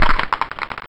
die_skeleton.ogg